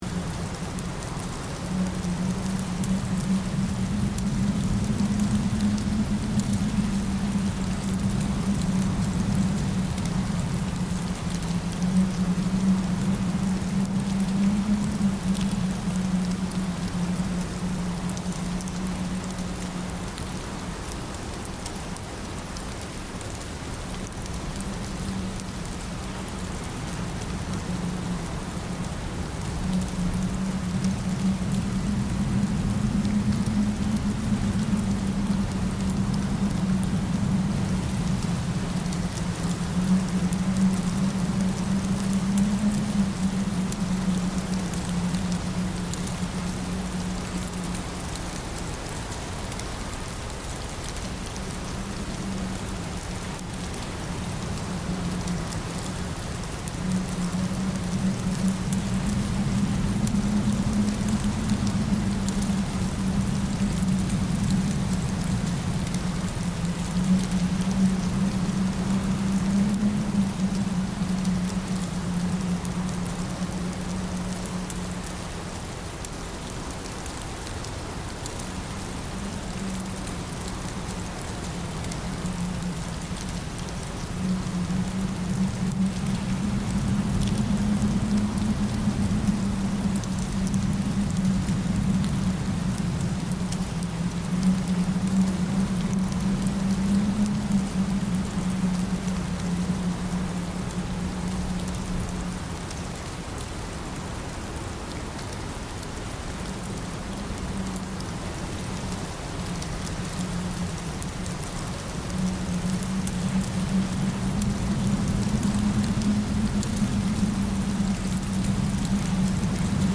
دانلود آهنگ بارش برف و بوران زمستانی از افکت صوتی طبیعت و محیط
دانلود صدای بارش برف و بوران زمستانی از ساعد نیوز با لینک مستقیم و کیفیت بالا